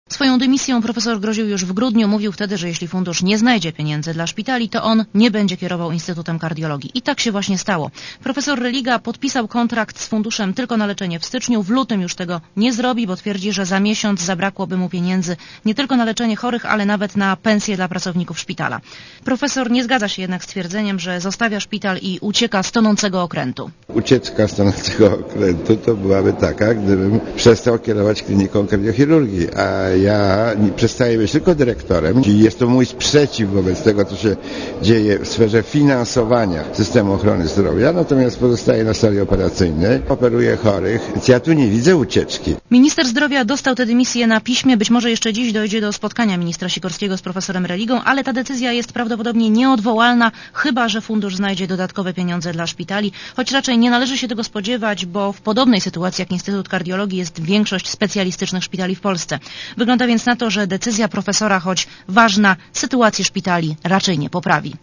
Posłuchaj relacji reporterki Radia Zet (252 KB)